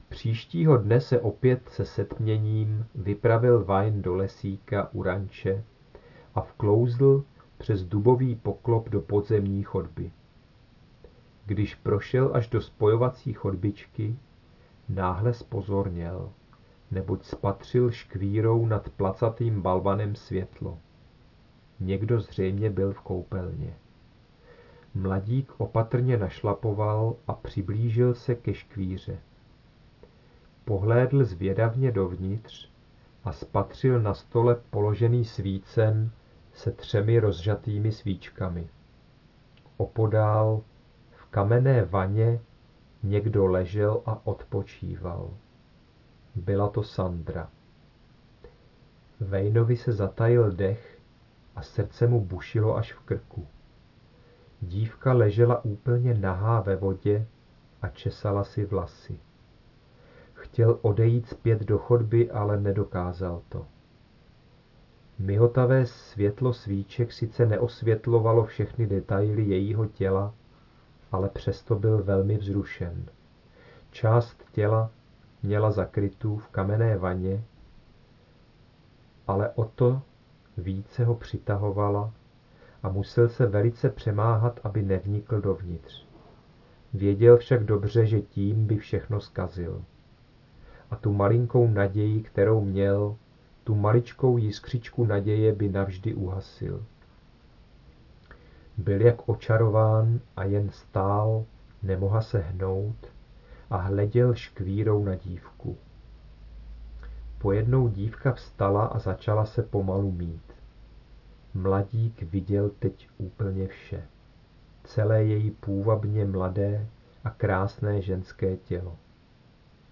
• audiokniha